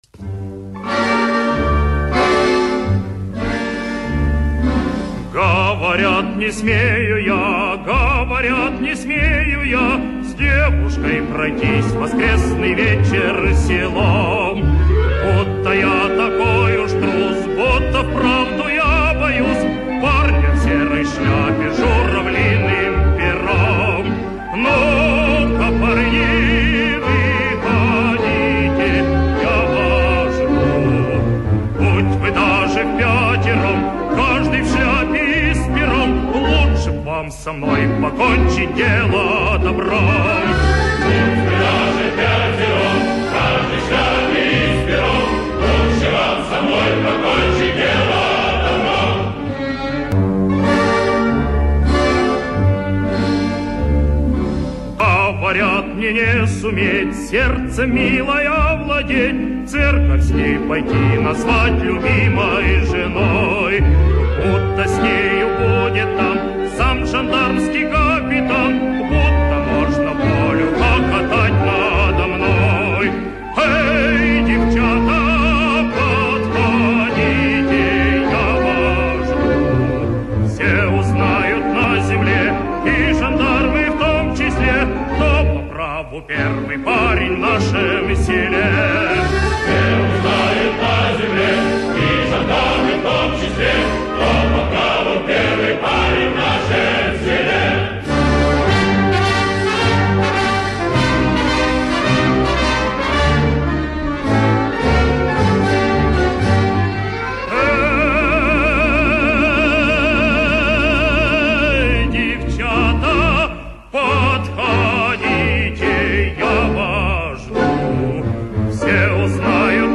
Венгерская народная песня
солист